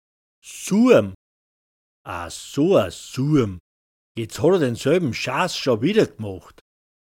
Surm [‚zuam] m Hastender, unverlässlicher, flausenhafter Mensch, Flegel.
Hörbeispiel „Surm“